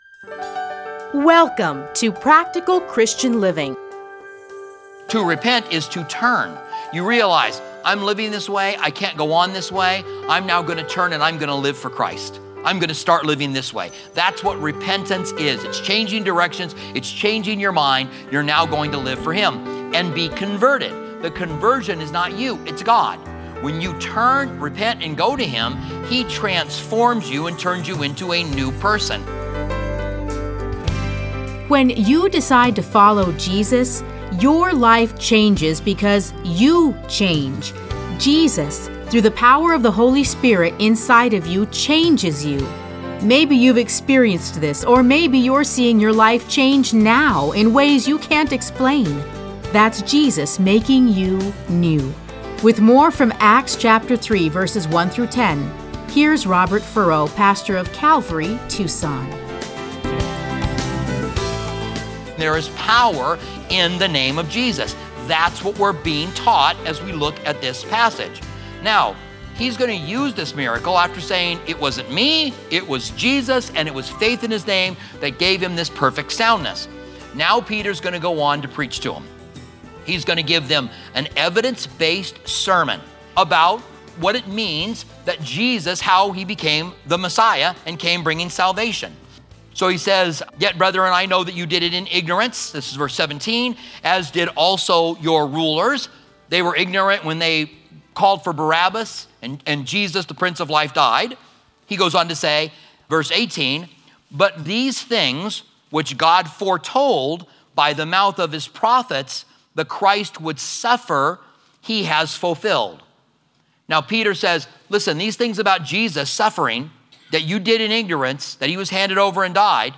Listen to a teaching from Acts 3:1-10.